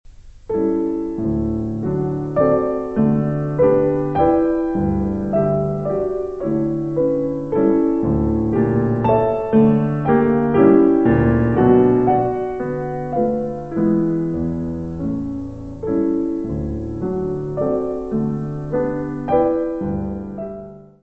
piano.